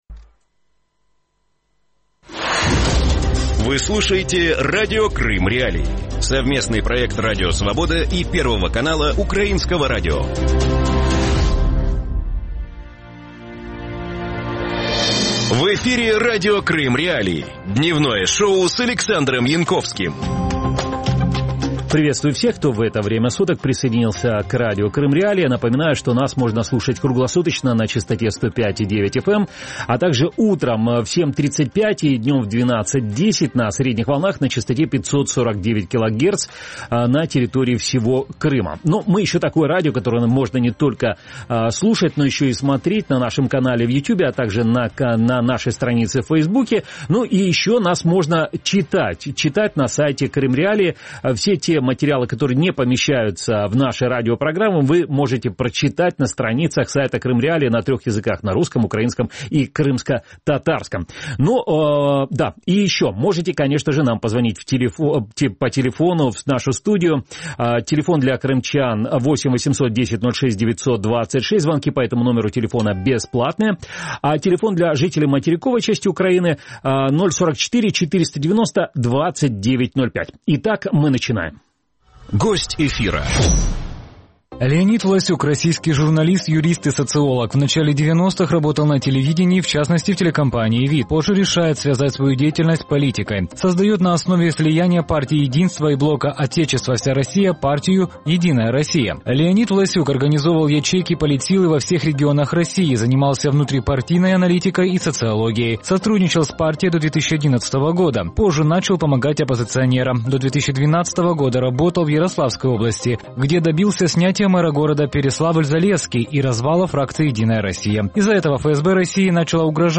Есть ли у Запада эффективные рычаги воздействия на Россию? Об этом – в эфире Радио Крым.Реалии – проекте «Дневное шоу» с 12:10 до 12:40.